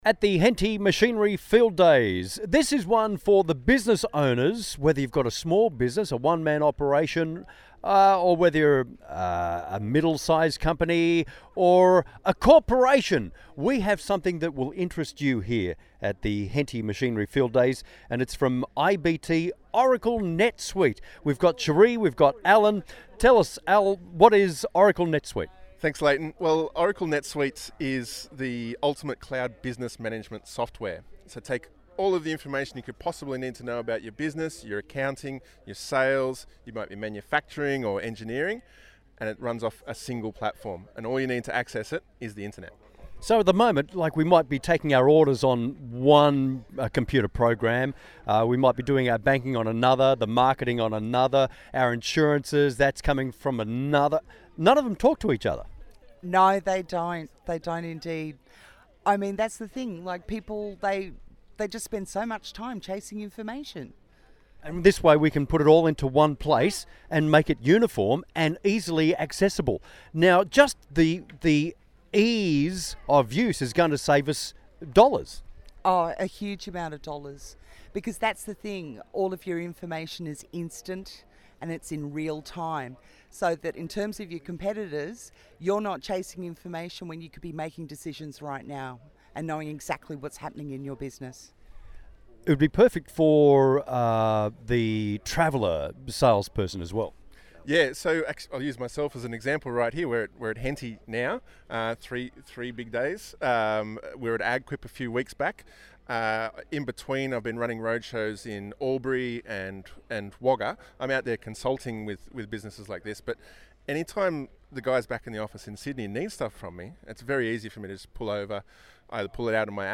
IBT-MMM-radio-interview-Henty2019
IBT MMM Radio Interview